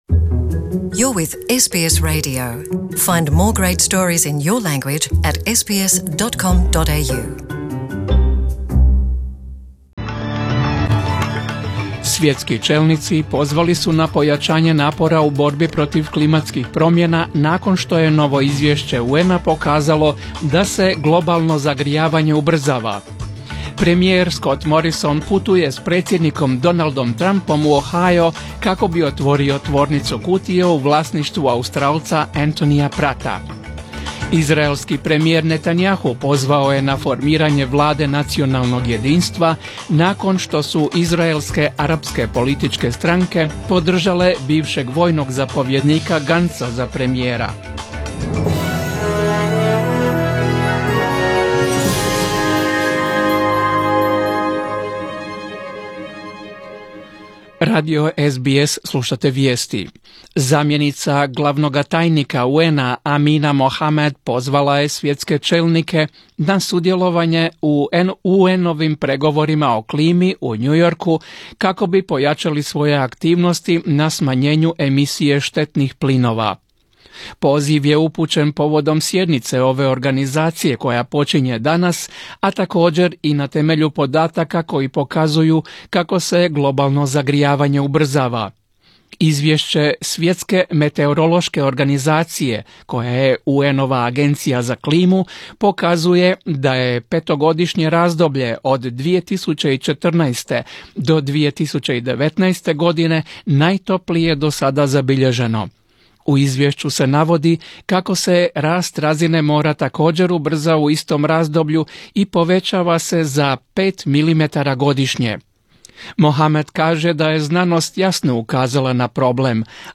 Vijesti 23.9.2019.
Vijesti radija SBS